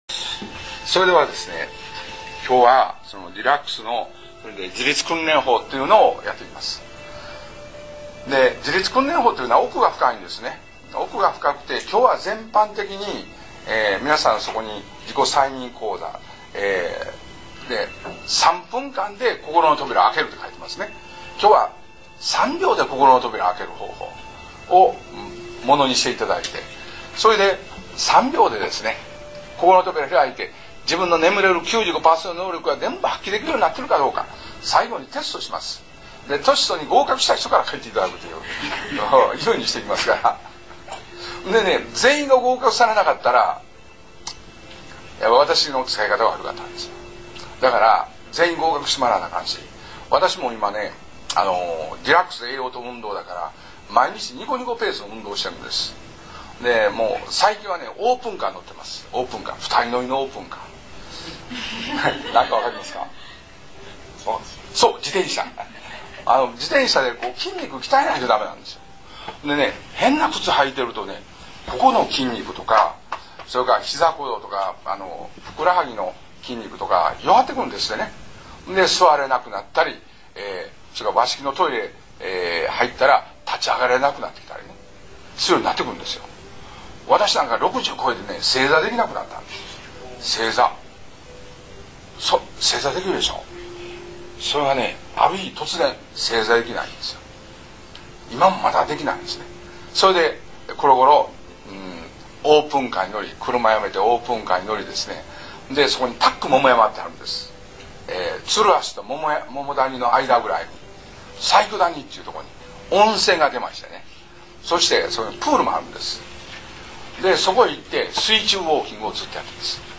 （カウンセラー様との対話・イメージトレーニング）
合計収録時間　１３７分３６秒　クレオ大阪中央にて収録